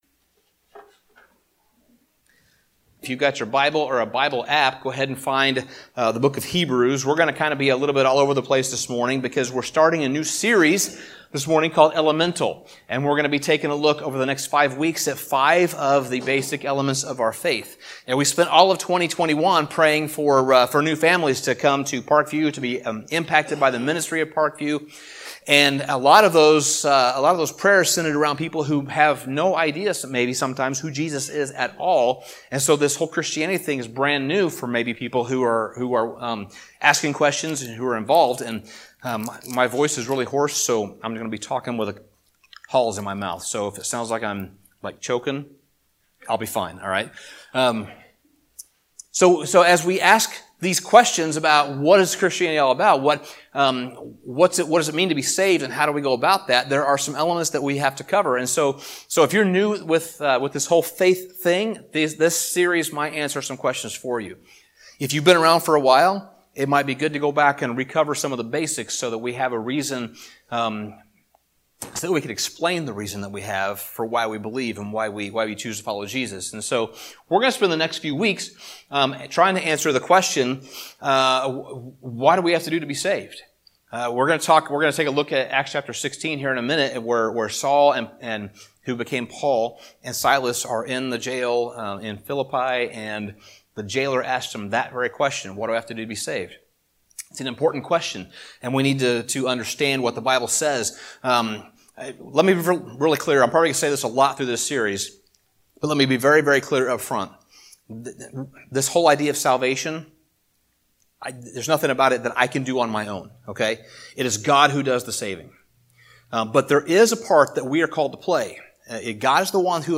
Sermon Summary The first element that we need to look at is the element of faith. Faith is a critical part of our salvation, and we need to understand just what it is.